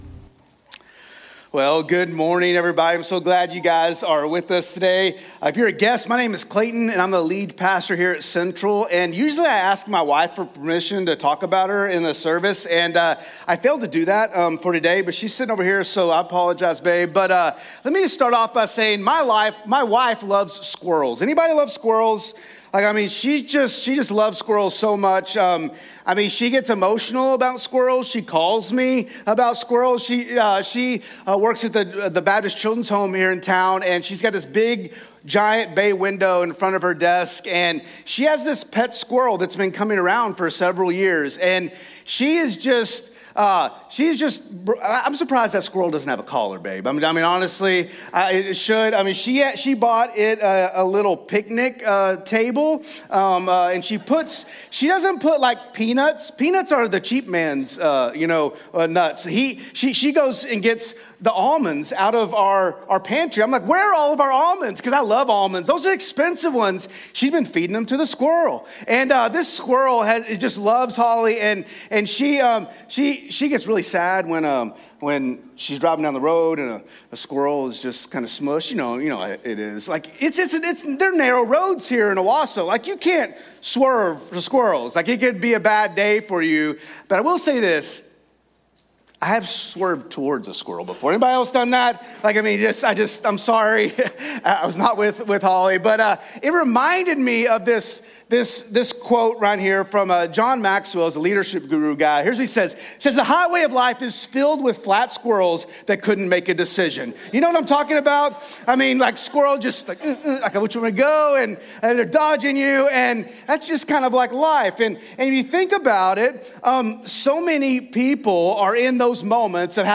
Sermons | Central Baptist Church Owasso